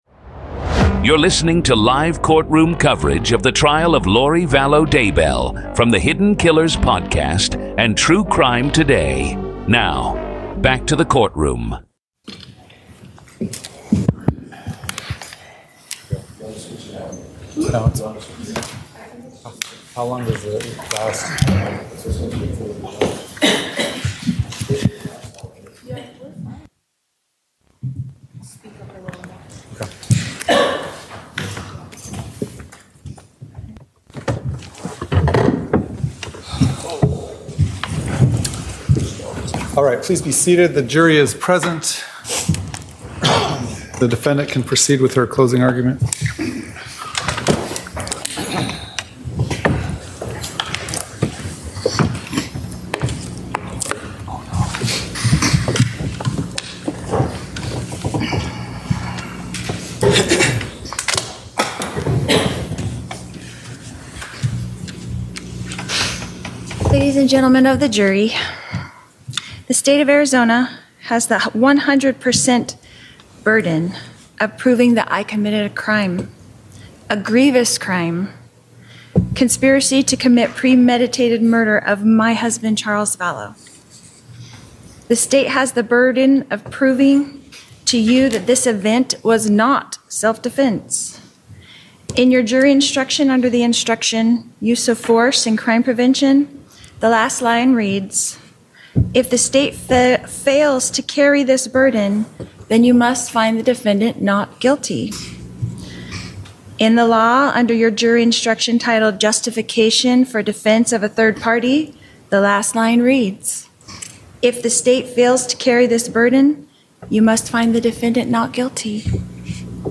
raw courtroom audio from closing arguments